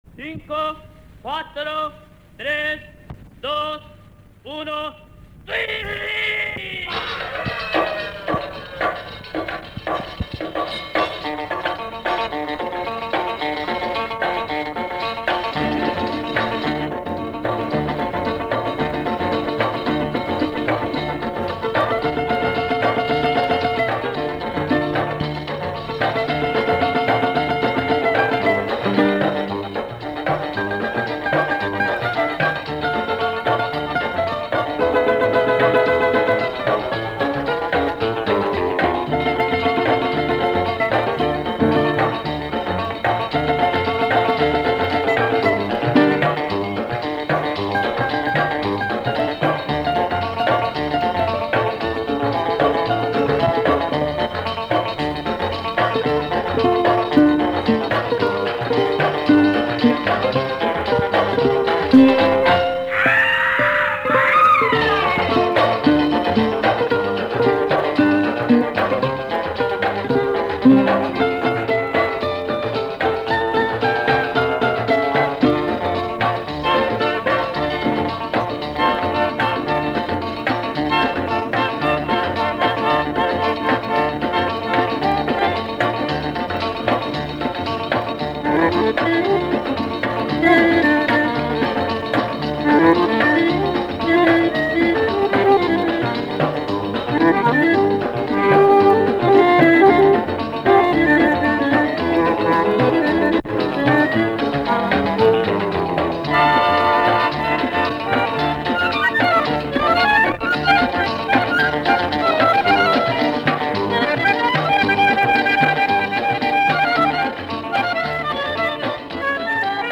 Вначале песни идёт отсчёт : cinco cuatro tres dos uno sí
Мелодия изначально была на рёбрах.